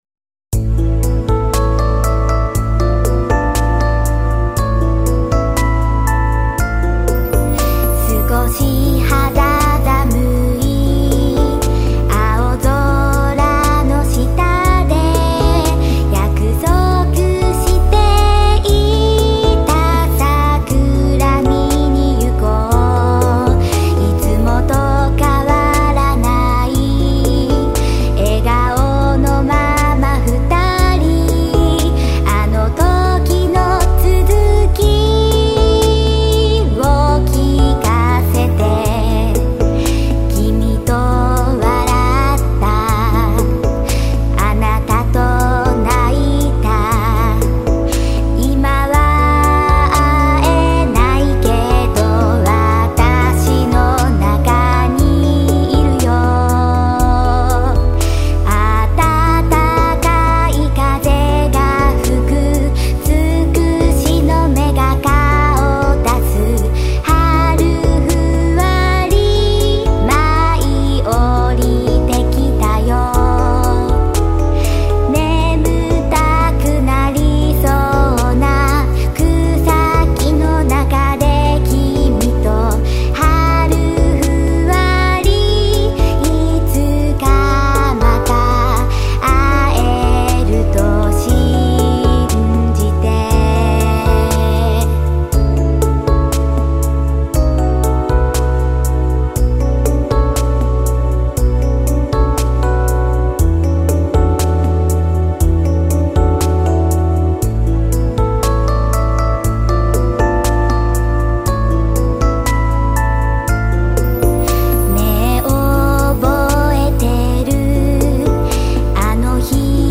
ＢＧＳ用途なので、アレンジを抑えた仕様です。
・マイク：RODE NT2（静岡県藤枝市：いちかわ楽器